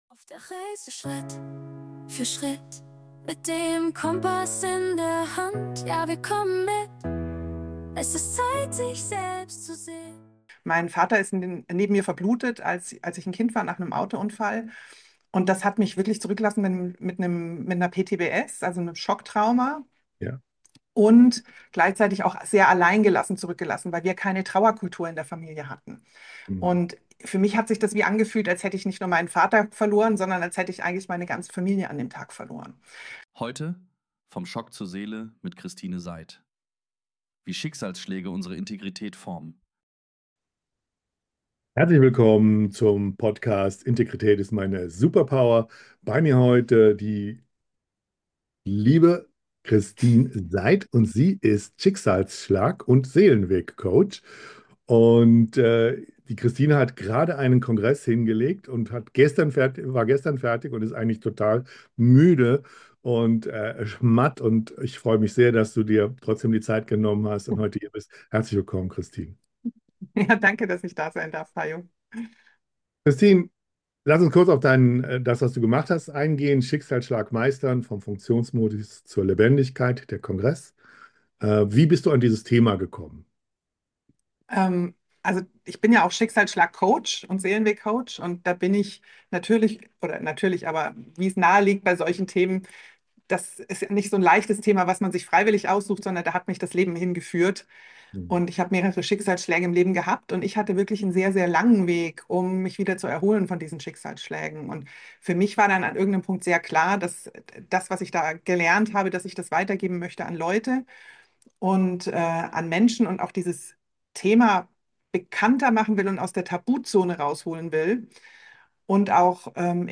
Dieses Interview ist kein Hochglanz-Coaching-Talk.